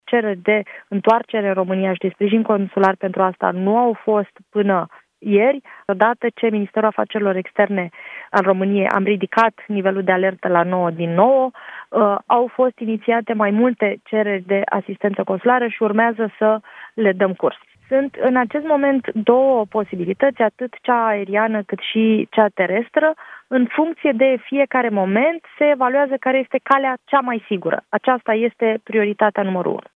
Ministrul de Externe, Oana Țoiu: „Odată ce Ministerul de Externe al României a ridicat nivelul de alertă la nouă din nouă, au fost inițiate mai multe cereri de asistență consulară”